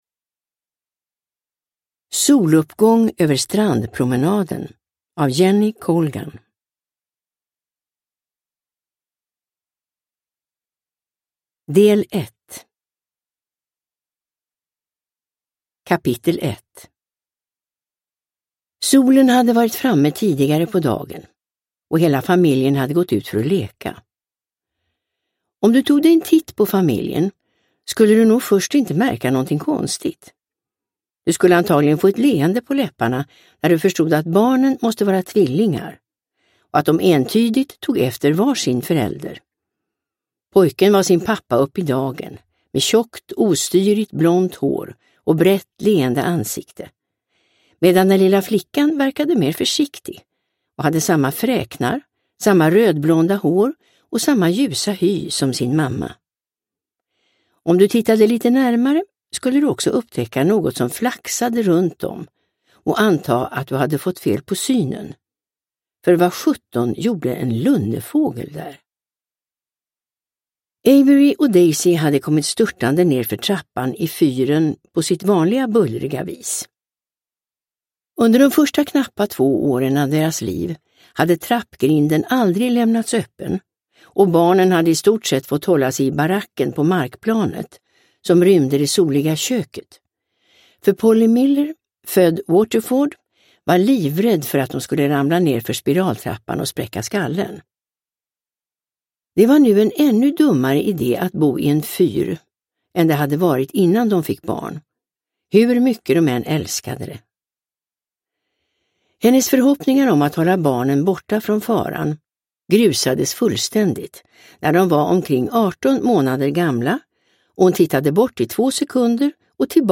Soluppgång över strandpromenaden – Ljudbok – Laddas ner
Uppläsare: Irene Lindh